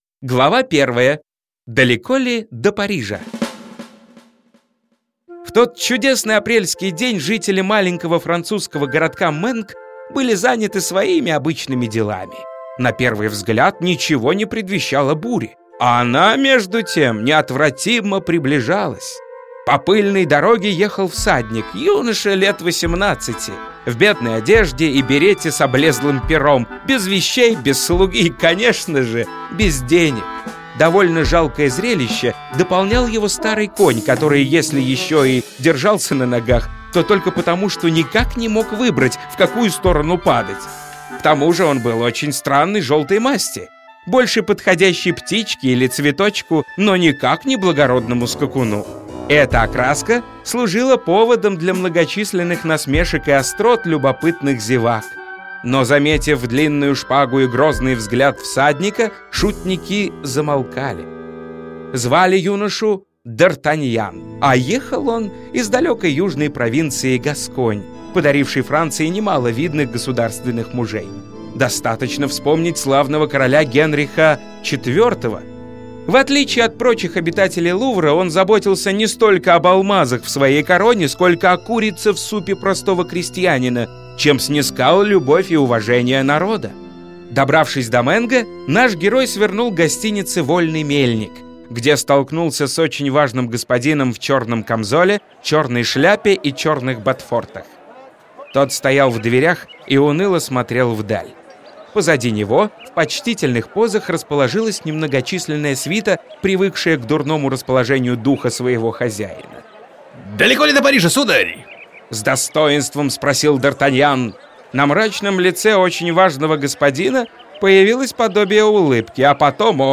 Аудиокнига Три мушкетера (спектакль для детей) | Библиотека аудиокниг
Aудиокнига Три мушкетера (спектакль для детей) Автор Александр Дюма Читает аудиокнигу Лев Дуров.